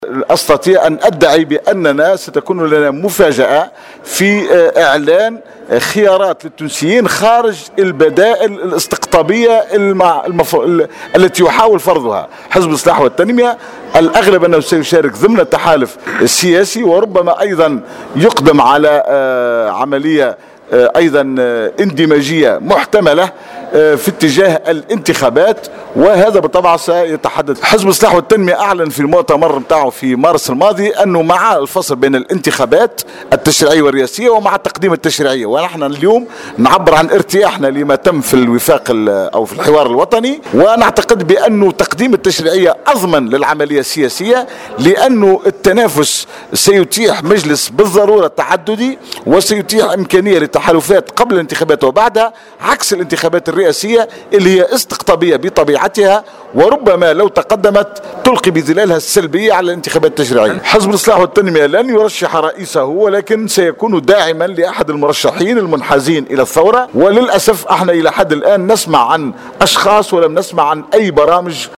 Dans une déclaration accordée au correspondant de Jawhara FM ce dimanche 15 juin 2014, Mohamed Goumani, président du parti de la réforme et du développement, a indiqué que les alliances de son parti créeront de réelle surprises sur la scène politique tunisienne.